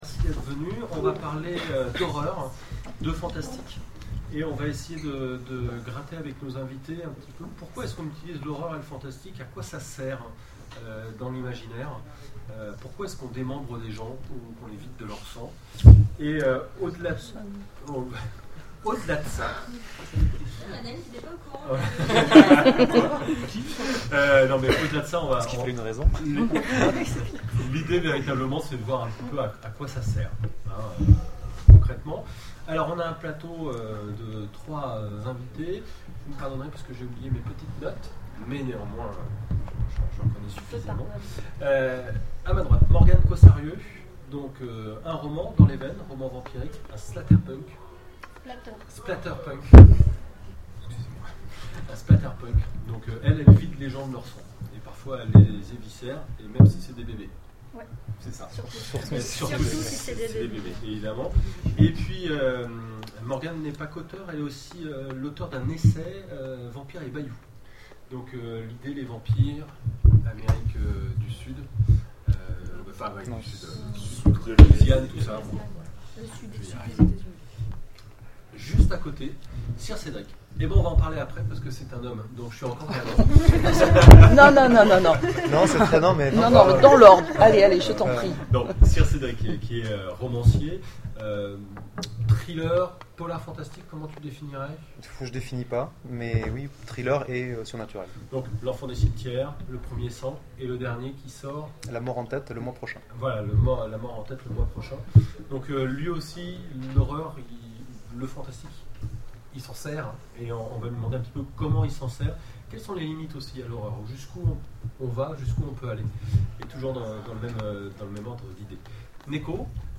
Octogones 2013 : Conférence Imaginer et jouer avec l'innomable
Horreur, Fantastique, Littérature et Jeu de Rôle : le mariage idéal ? Pour décrypter les liens qui existent entre les mondes de l'imaginaire, la sphère ludique et l'horreur, nous convions autour de la table plusieurs auteurs et créateurs qui ont su s'affirmer, au fil de leurs créations, comme des spécialistes du genre horrifique.